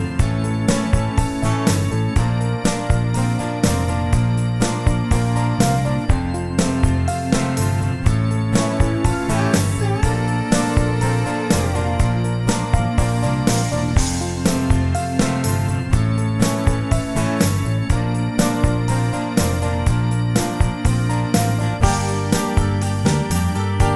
Two Semitones Down Pop (1990s) 4:40 Buy £1.50